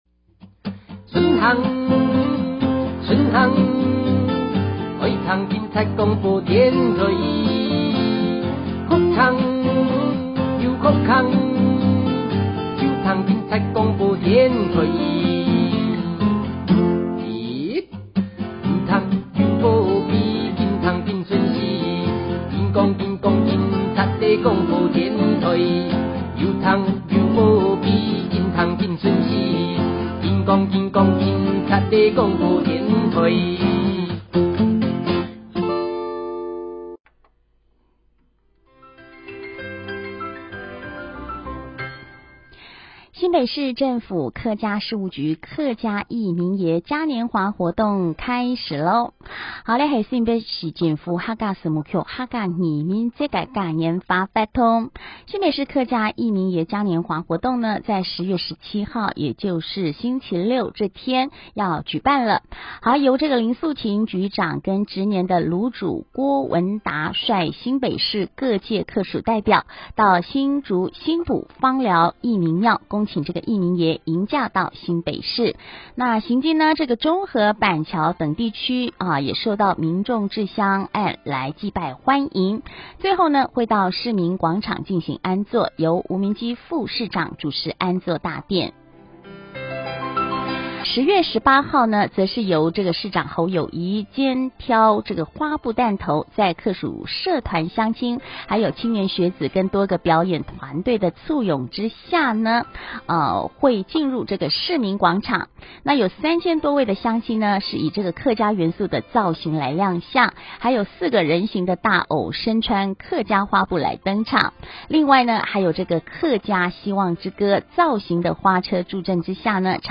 警察廣播電台 - 109年新北市客家義民爺嘉年華活動口播 | 新北市客家文化典藏資料庫